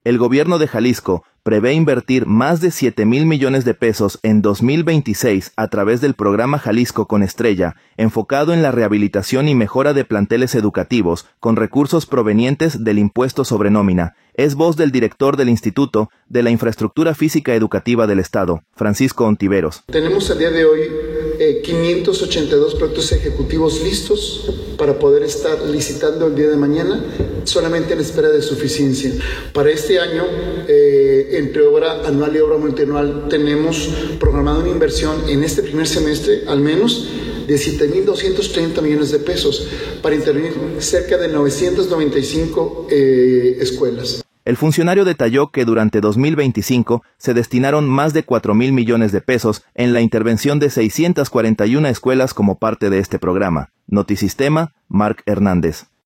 El Gobierno de Jalisco prevé invertir más de 7 mil millones de pesos en 2026 a través del programa “Jalisco con Estrella”, enfocado en la rehabilitación y mejora de planteles educativos con recursos provenientes del Impuesto sobre Nómina. Es voz del director del Instituto de la Infraestructura Física Educativa del Estado, Francisco Ontiveros.